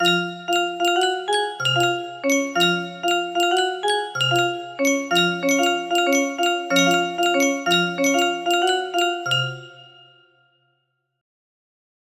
totally not stolen melody music box melody
Full range 60